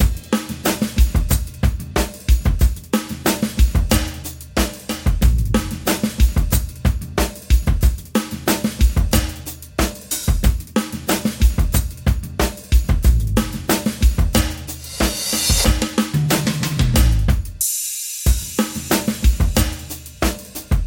描述：我用大量的效果器演奏的两段旋律的混合。
标签： 92 bpm Rave Loops Drum Loops 3.51 MB wav Key : Unknown
声道立体声